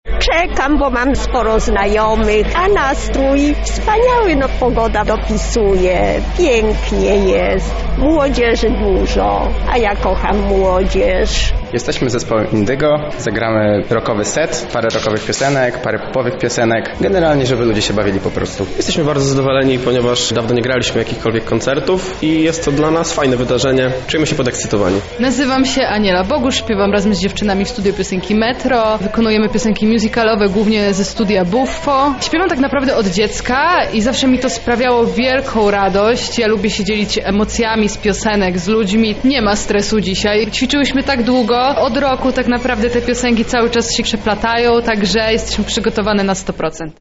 Wiolonczele, perkusje i śpiew przeplatały się pośród przyrody.
• Z grania czerpiemy samą przyjemność, więc nastroje są rewelacyjne – zapewniają członkowie zespołu Indygo.
Dźwięki płynęły do nas z Muszli Koncertowej w Ogrodzie Saskim.